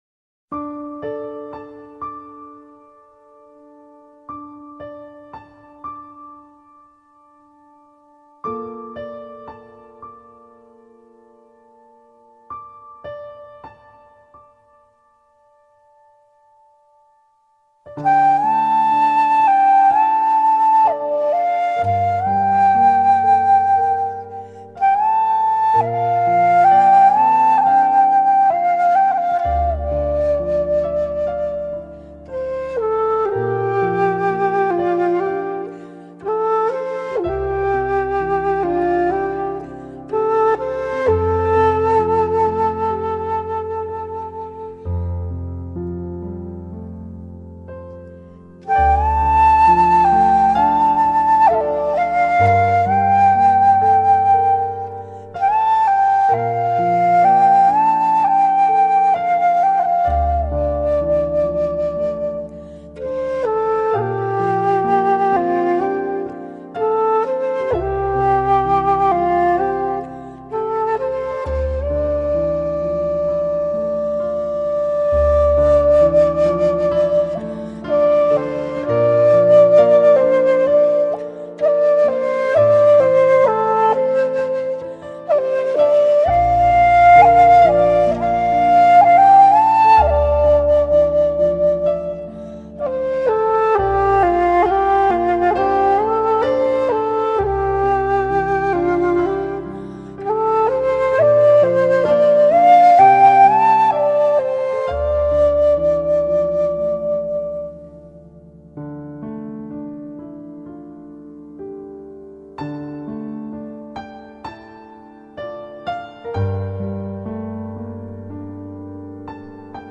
Give it a listen, and sink into the peaceful soundscape…
Can’t you feel yourself relaxing already?